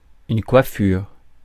Ääntäminen
IPA: /kwa.fyʁ/